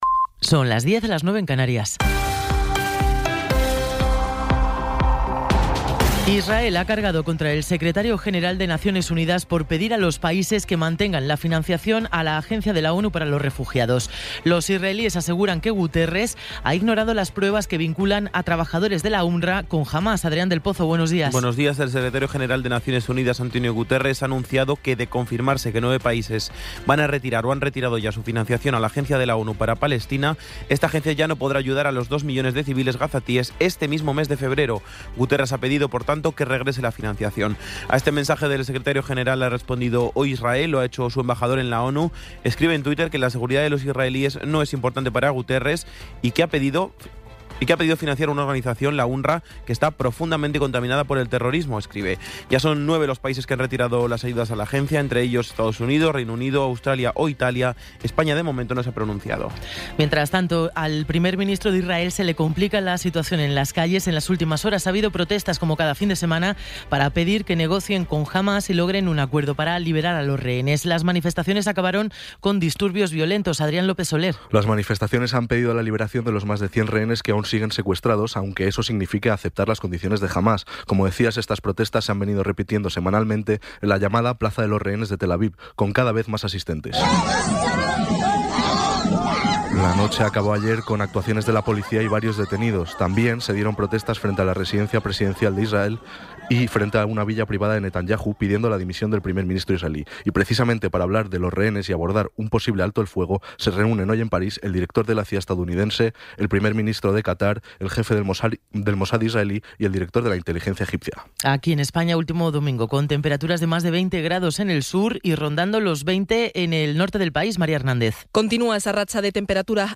Resumen informativo con las noticias más destacadas del 28 de enero de 2024 a las diez de la mañana.